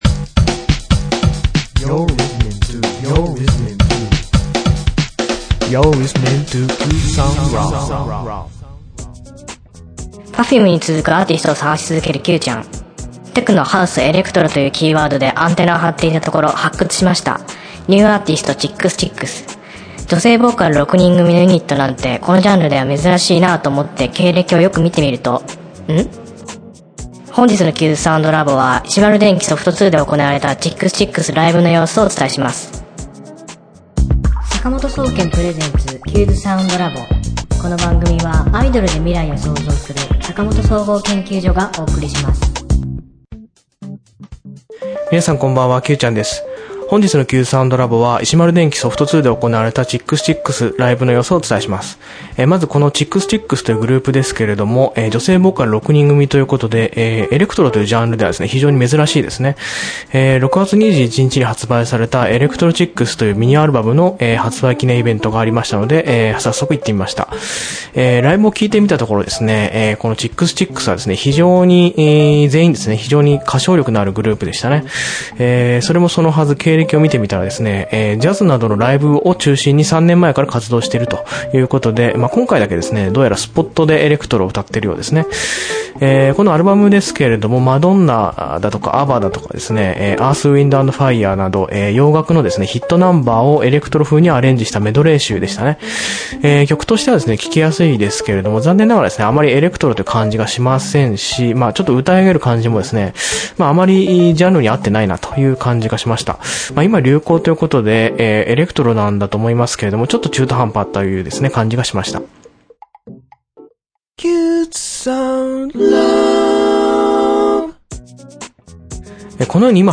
テクノ、ハウス、エレクトロというキーワードでアンテナを張っていたところ、発掘しました“Chix Chicks”。女性ボーカル６人組のユニットなんてこのジャンルでは珍しいなぁと思って、経歴をよく見てみると…。本日のQ’sSoundLab.は石丸電気ソフト２で行われたChix Chicksライブの様子をお伝えします。